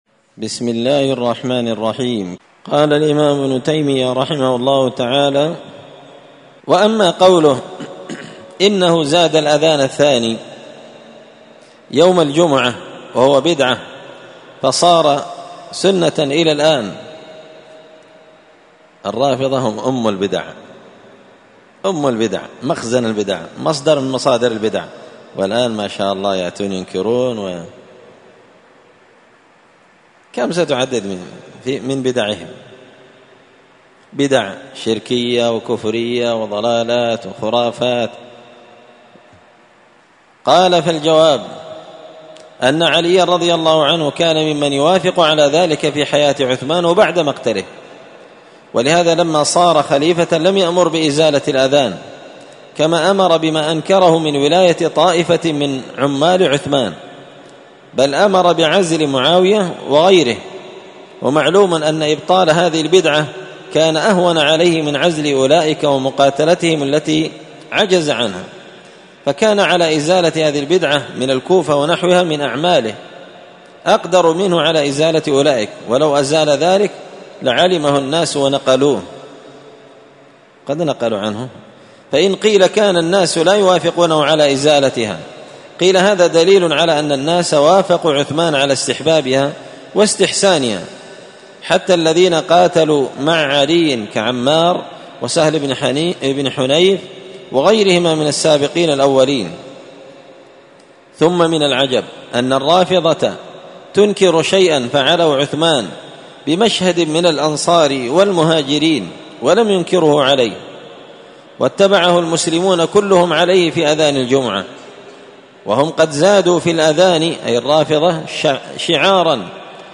الخميس 25 ذو الحجة 1444 هــــ | الدروس، دروس الردود، مختصر منهاج السنة النبوية لشيخ الإسلام ابن تيمية | شارك بتعليقك | 8 المشاهدات
مسجد الفرقان قشن_المهرة_اليمن